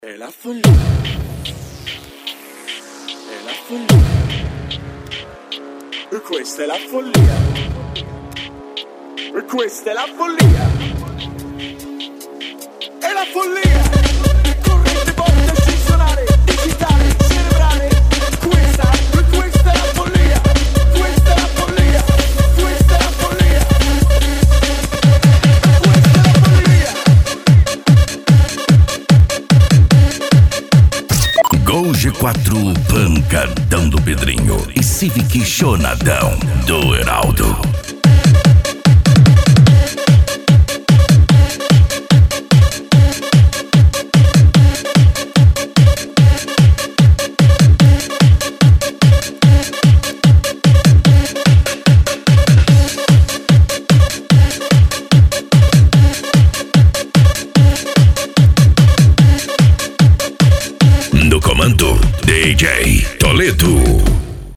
PANCADÃO